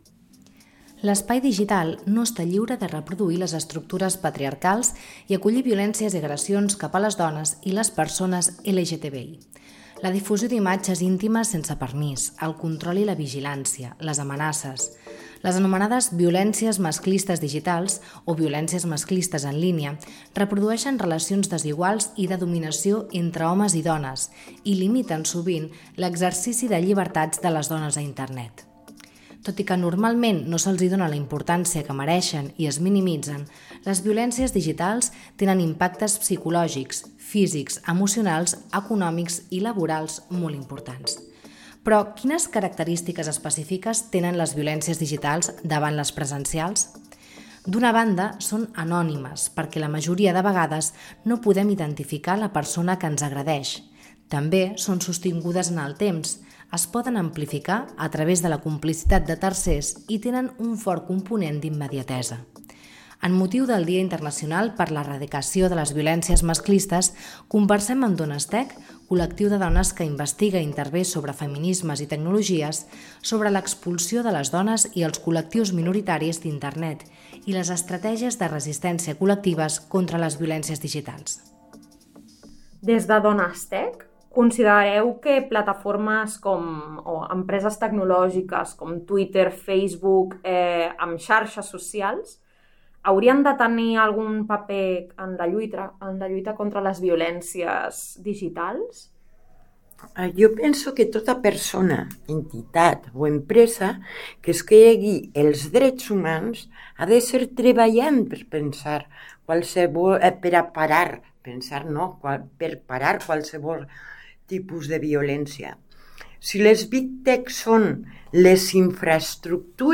Violències masclistes digitals: entrevista a Donestech (25N)